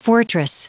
Prononciation du mot fortress en anglais (fichier audio)
Prononciation du mot : fortress